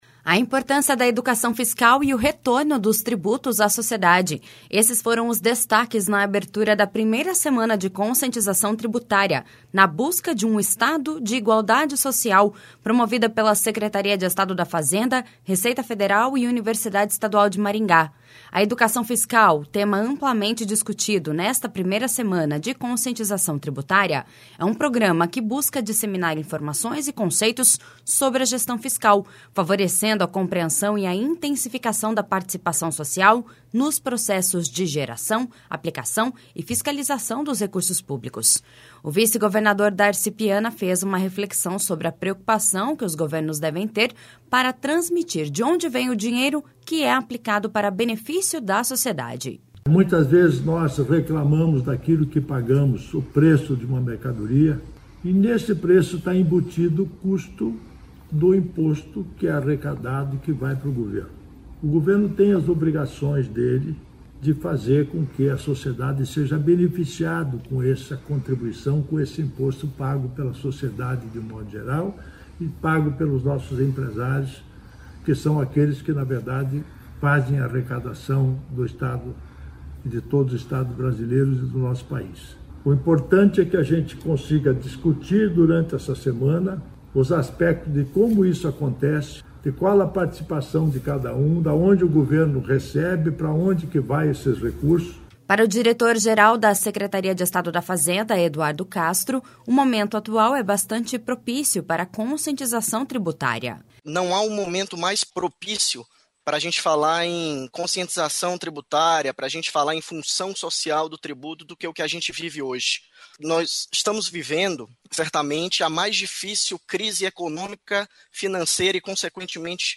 O vice-governador Darci Piana fez uma reflexão sobre a preocupação que os governos devem ter para transmitir de onde vem o dinheiro que é aplicado para benefício da sociedade.// SONORA DARCI PIANA.//
Para o diretor-geral da Secretaria de Estado da Fazenda, Eduardo Castro, o momento atual é bastante propício para a conscientização tributária.// SONORA EDUARDO CASTRO.//
O diretor da Receita Estadual, Roberto Tizzon, citou os programas que a Secretaria da Fazenda executa para proporcionar ao cidadão uma reflexão sobre os conceitos de cidadania fiscal, de uma forma leve, lúdica e de fácil compreensão.// SONORA ROBERTO TIZZON.//